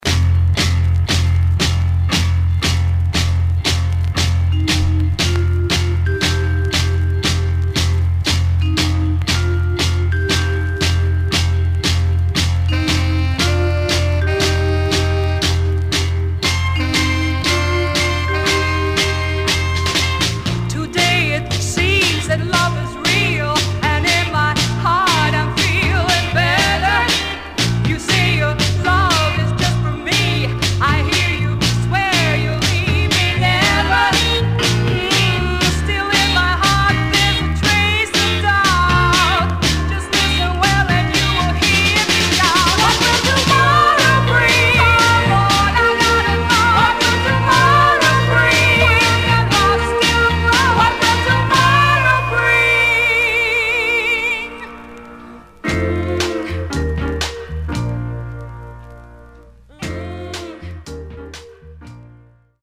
Surface noise/wear
Mono
Soul